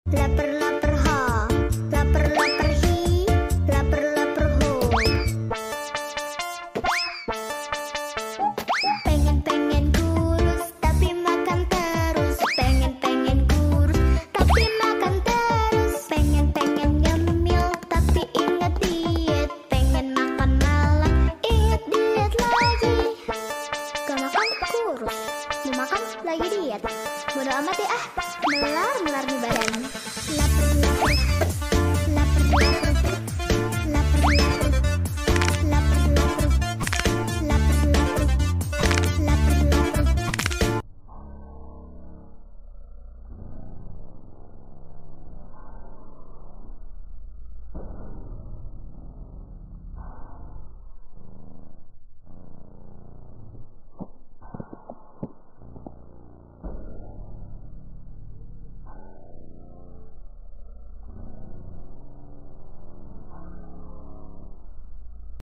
New Red Bowl Cake Making sound effects free download
Relax and enjoy the super satisfying red bowl cake making and crushing with ASMR sand. 🎂✨ Perfect for stress relief, calming vibes, and satisfying moments!